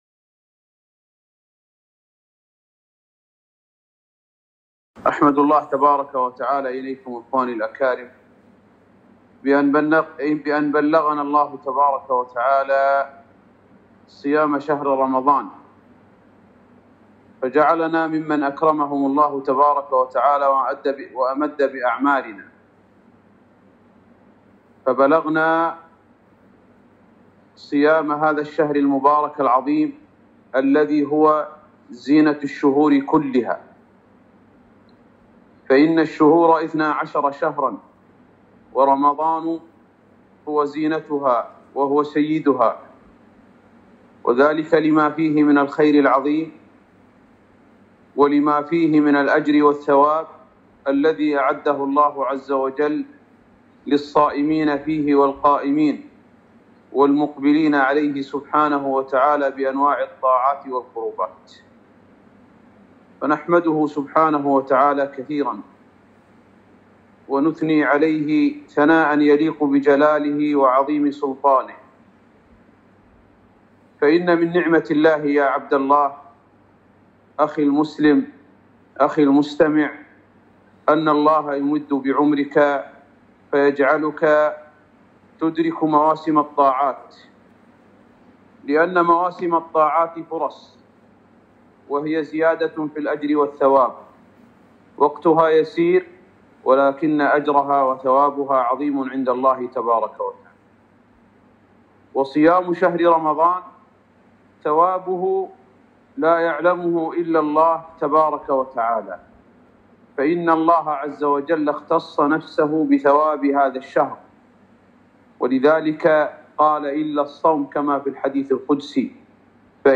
محاضرة - استغلال الوقت في رمضان